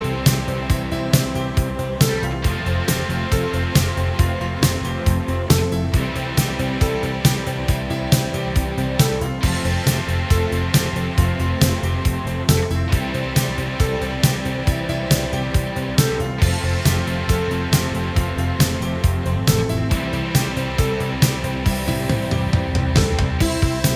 Minus Lead Guitar Pop (1980s) 3:27 Buy £1.50